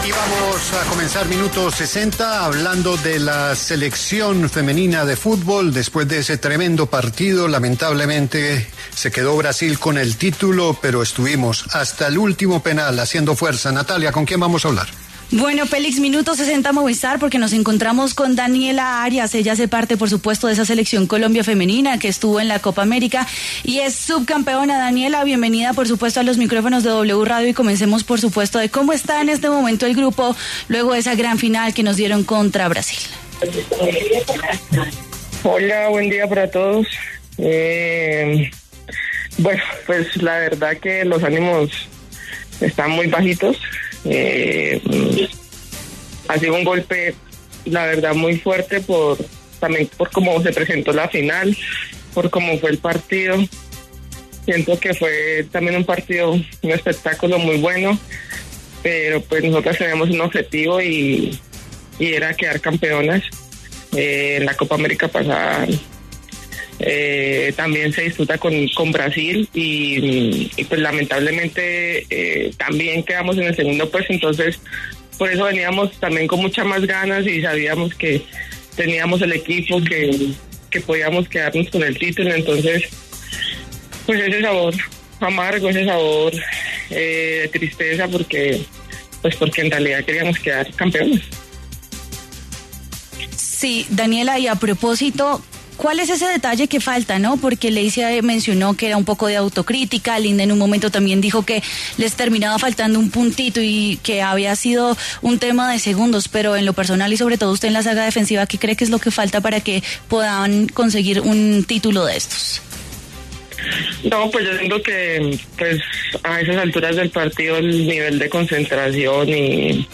Este lunes, 4 de agosto, habló en los micrófonos de La W la futbolista de la Selección Colombia femenina, Daniela Arias, quien se refirió al subcampeonato de la ‘Tricolor’ en la Copa América tras caer 5-4 en los penales contra Brasil.